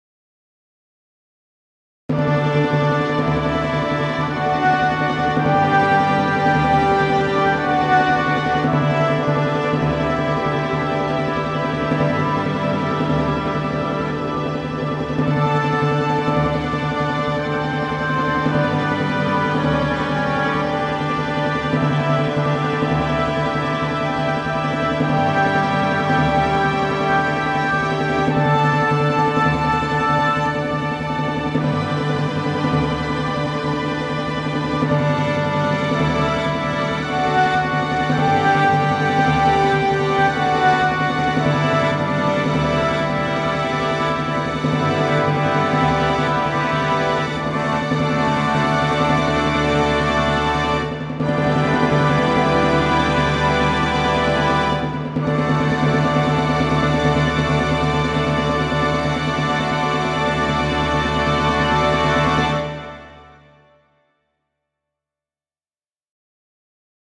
現在の環境を列記してみると、DAW ソフトとして SONAR HOME STUDIO 6、ソフト音源に Miroslav Philharmonik CESample Tank、そして VOCALOID 各キャラ。
DTM ソフトの研究をかねて、この交響曲の第4楽章のすばらしい終結部を打ち込んでみました。
それにしても、この金管楽器が高らかに歌い上げる凱歌はすごいですね。